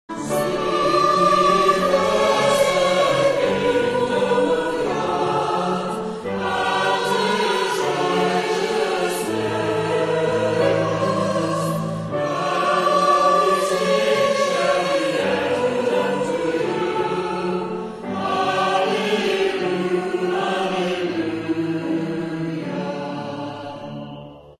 Maybole Churches have a long history of Choral Singing.
Praise music performed in these historic buildings.